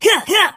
gus_atk_vo_02.ogg